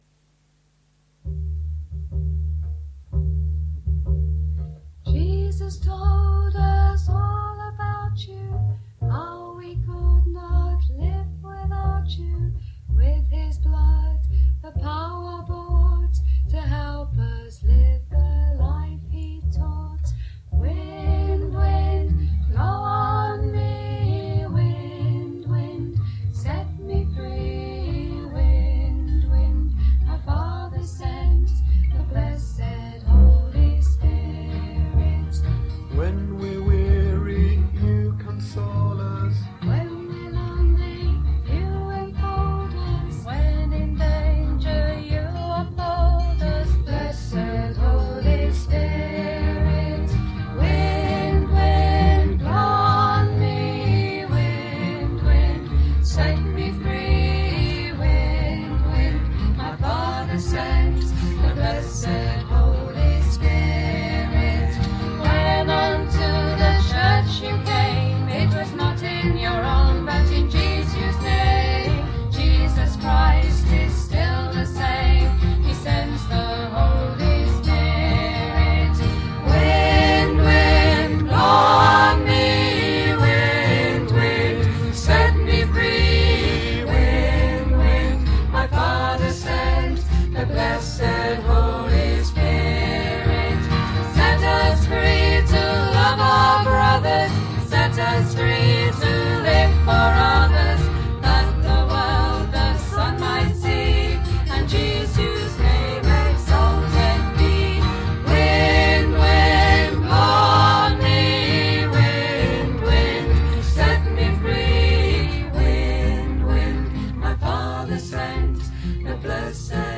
These recordings were made in the 1970s
double bass and bass vocals
alto vocals
guitar and vocals
soprano vocals
guitar, banjo and vocals